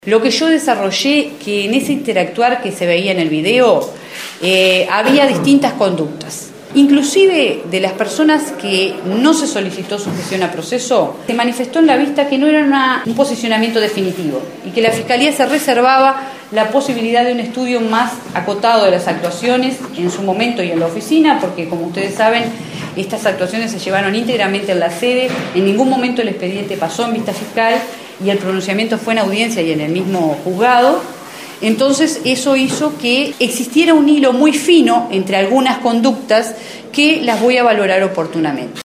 En conferencia de prensa, la fiscal Umpiérrez dijo que entiende que los funcionarios aplicaron un trato "cruel, inhumano y degradante" para con los internos en base a lo que se ve en el video y la información que recabaron de más de 50 testimonios, entre internos, indagados y testigos.